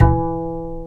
Index of /90_sSampleCDs/Roland - Rhythm Section/BS _Jazz Bass/BS _Ac.Fretless